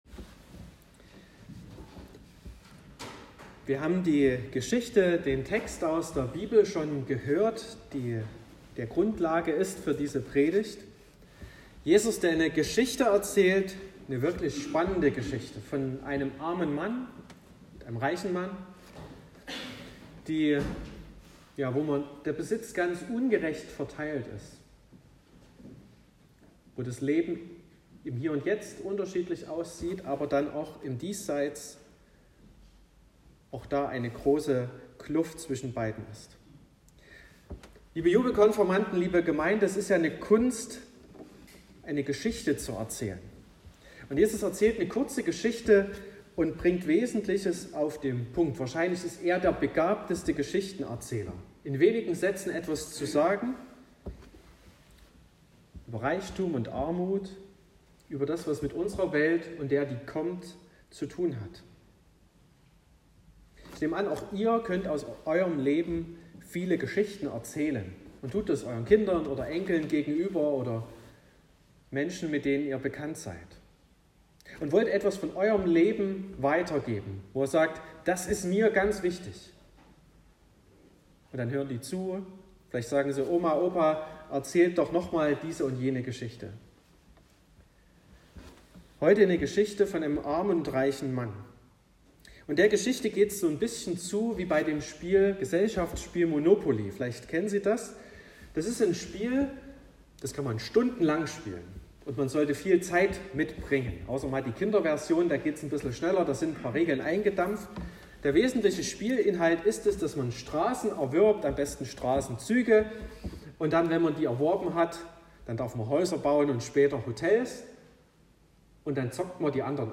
19.06.2022 – Gottesdienst
Predigt (Audio): 2022-06-19_Reicher_Mann_und_armer_Lazarus.m4a (6,2 MB)